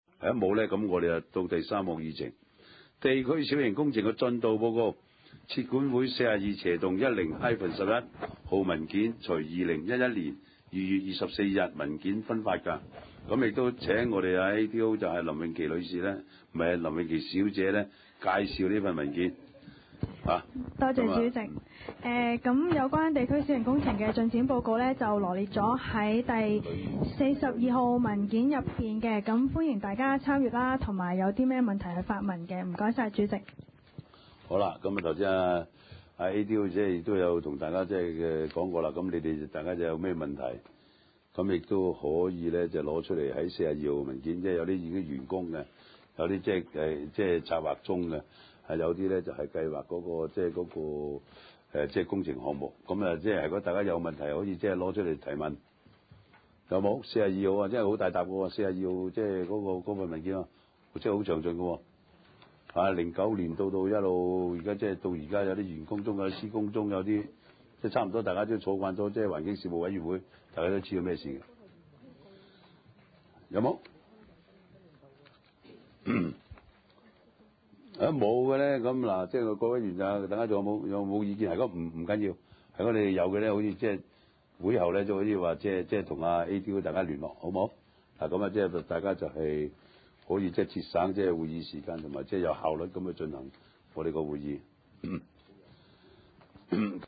地區設施管理委員會第二十次會議
荃灣民政事務處會議廳